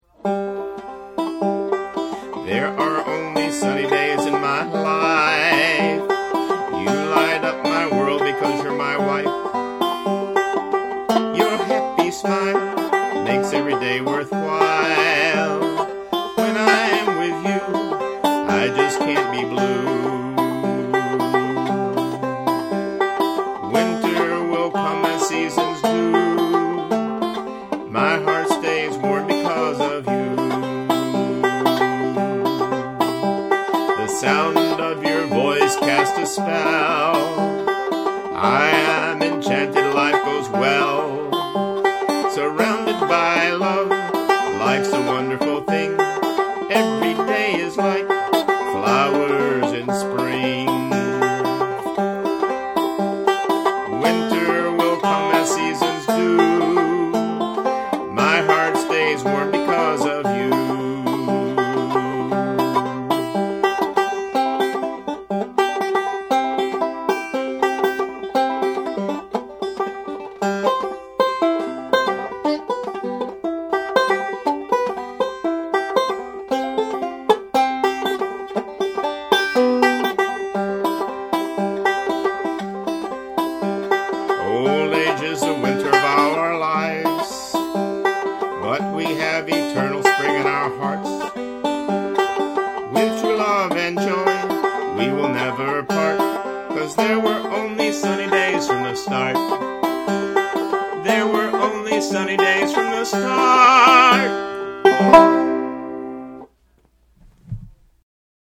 5-string/vocal lovesong